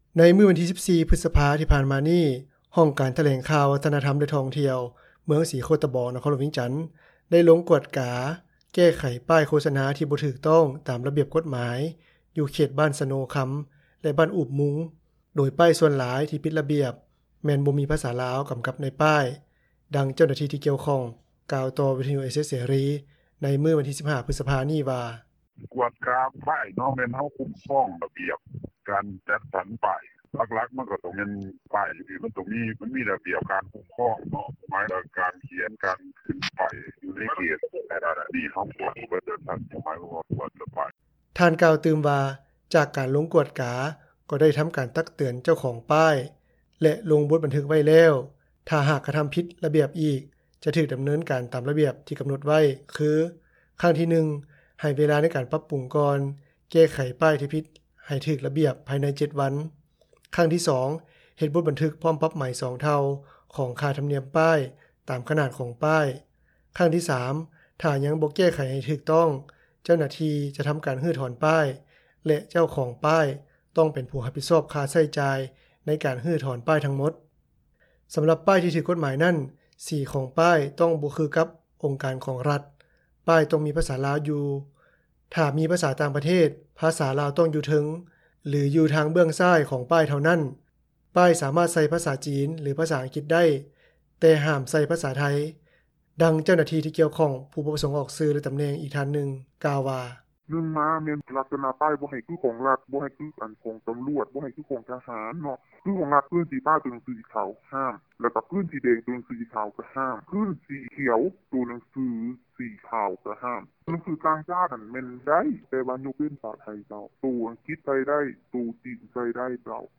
ດັ່ງເຈົ້າໜ້າທີ່ ທີ່ກ່ຽວຂ້ອງ ກ່າວຕໍ່ວິທຍຸ ເອເຊັຽ ເສຣີ ໃນມື້ວັນທີ 15 ພຶສພາ ນີ້ວ່າ: